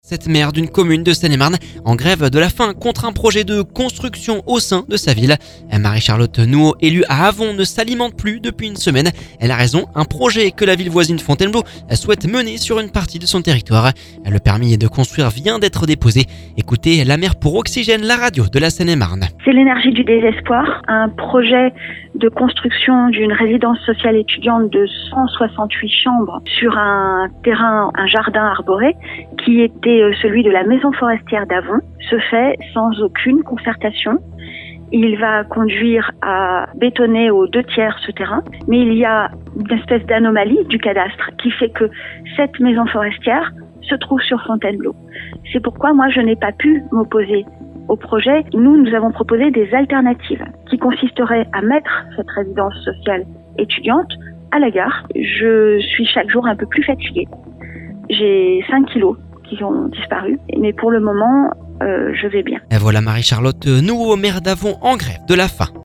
Ecoutez la maire pour Oxygène, la radio de la Seine-et-Marne.